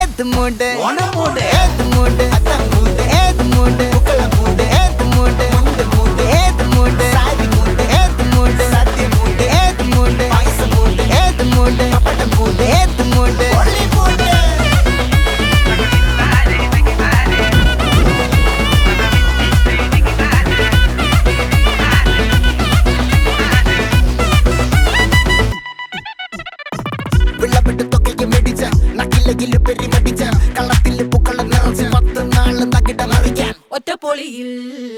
best flute ringtone download